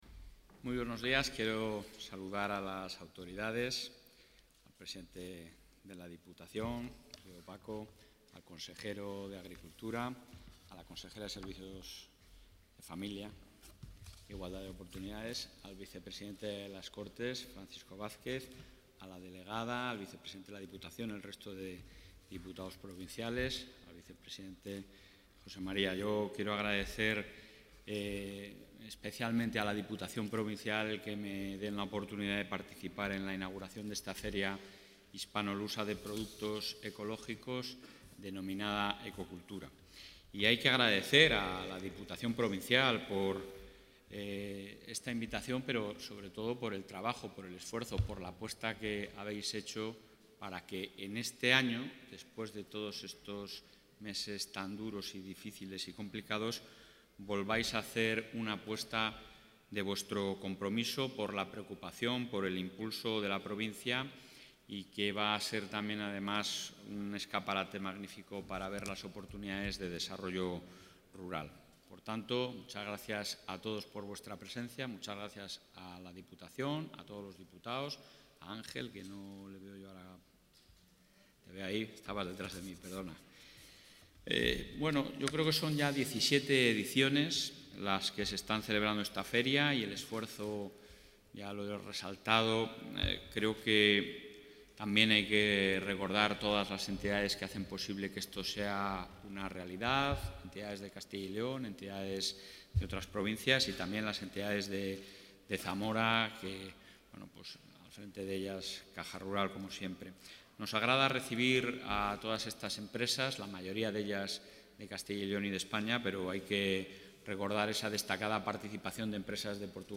Audio presidente.
El presidente de la Junta de Castilla y León, Alfonso Fernández Mañueco, ha mostrado su apoyo expreso a la producción ecológica como apuesta de futuro para la Comunidad. Así lo ha afirmado en la inauguración de la Feria Hispanolusa de Productos Ecológicos ECOCULTURA, que tras el parón de 2020 por la pandemia reunirá estos días en Zamora a productores de la mayoría de las Comunidades Autónomas españolas y una destacada presencia de Portugal.